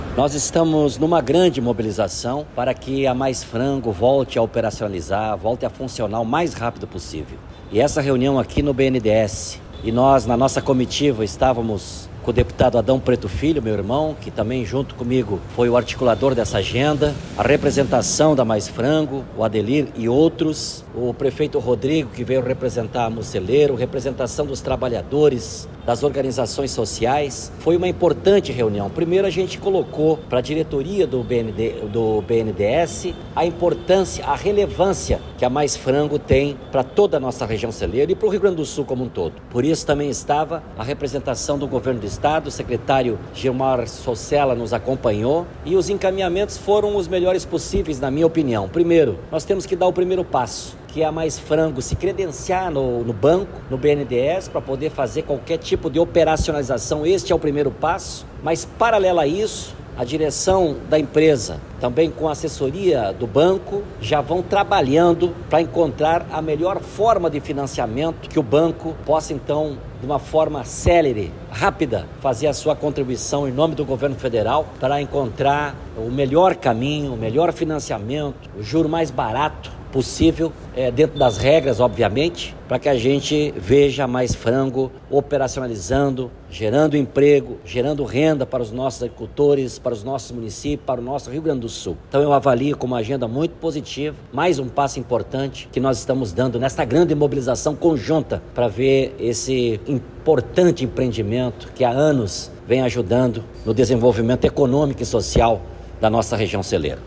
Sonora-Edegar-Pretto-Reuniao-BNDES-Mais-Frango.mp3